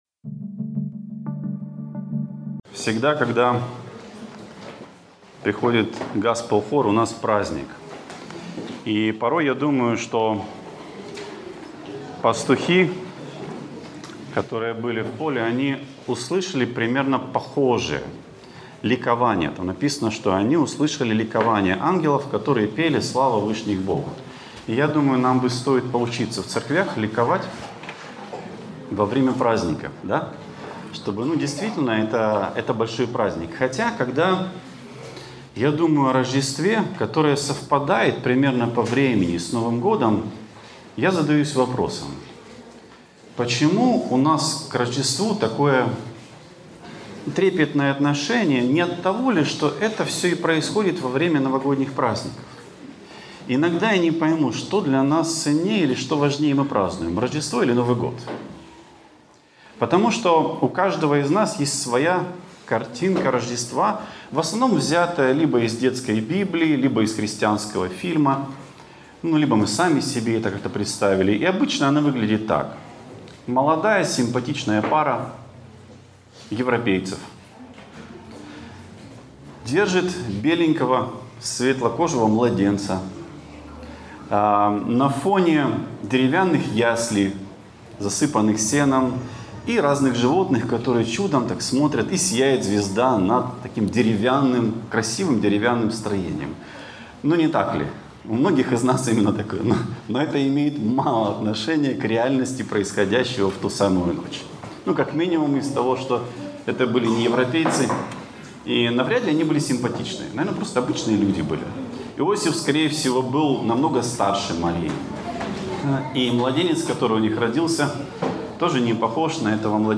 «Преображение» | Церковь евангельских христиан-баптистов